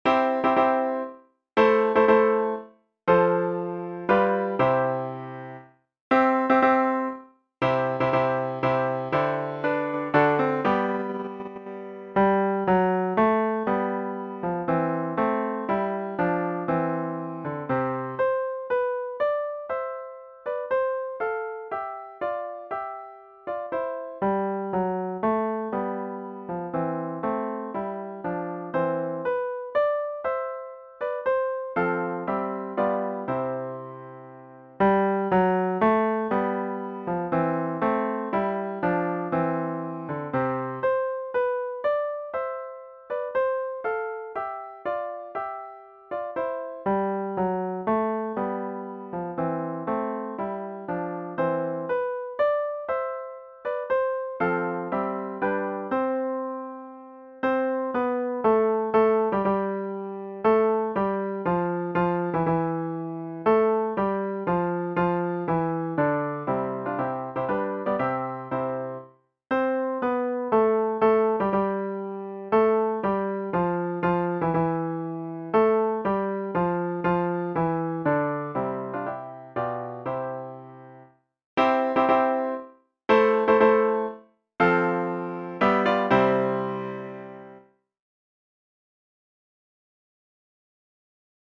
Аранжировка для хора